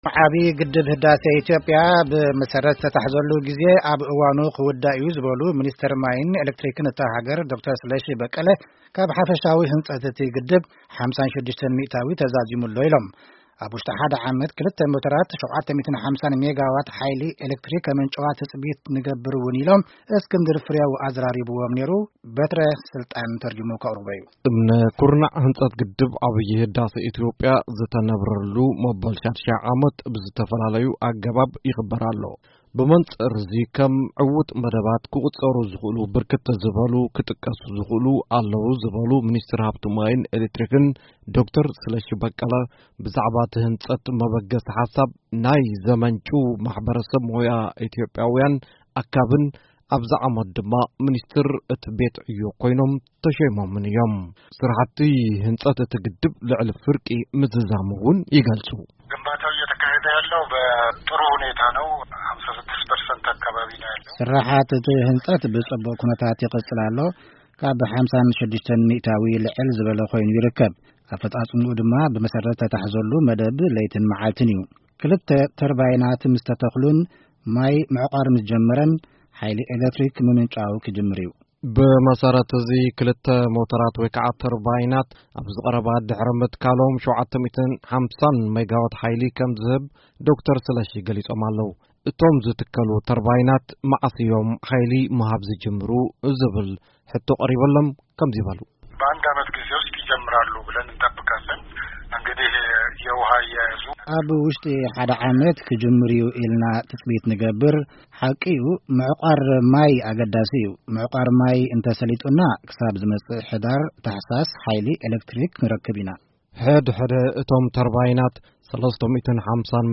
ንኣቐባሊና ዘዘራቡ ሚኒስትር ማይን ኤለክትሪክን ኢትዮጵያ ዶ/ር ስለሺ በቀለ ከምዝገለጽዎ ’ካብ ሓፈሻዊ ህንጸት’ቲ ግድብ 56 ሚእታዊ ተዛዚሙ’ሎ፣ ክልተ ተርባይናት ኣብ ውሽጢ ዓመት ተተኺሎም 750 ሜጋዋት ሓይሊ ኤለክትሪክ ከምንጭው’ዮም’ ኢሎም።